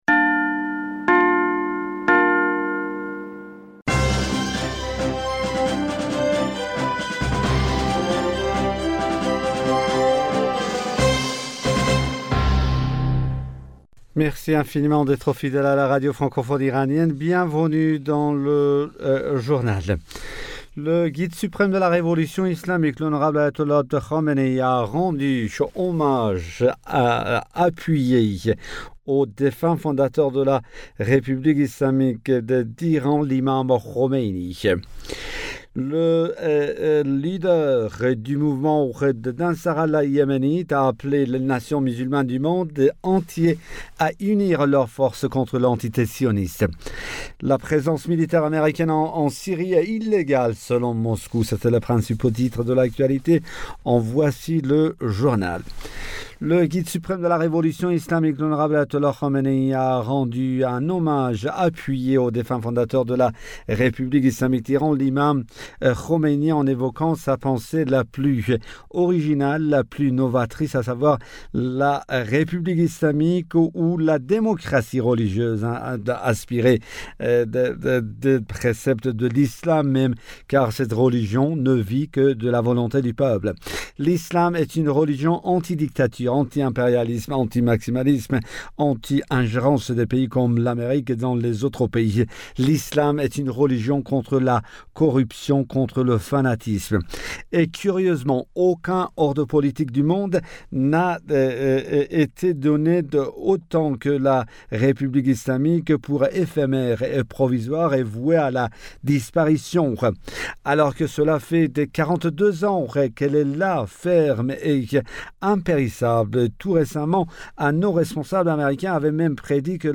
Bulletin d'information du 04 Juin 2021